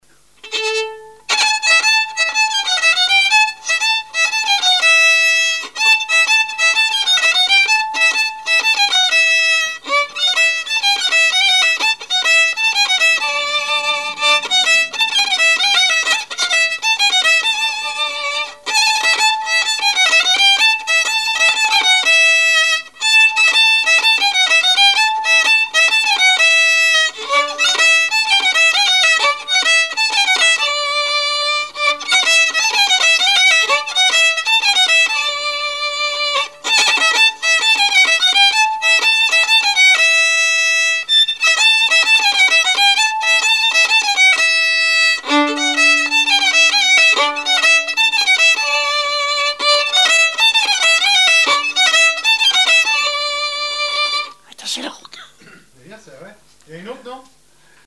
branle : courante, maraîchine
Répertoire musical au violon
Pièce musicale inédite